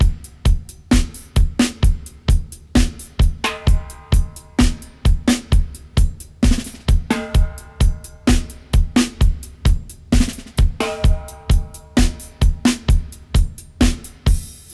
129-FX-04.wav